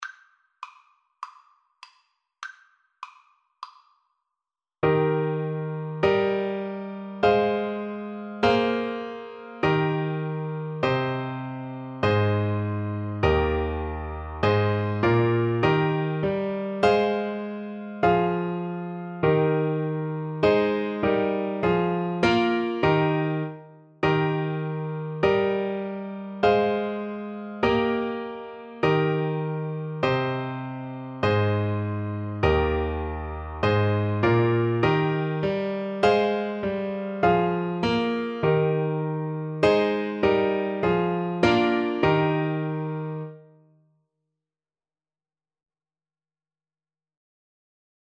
4/4 (View more 4/4 Music)
Moderato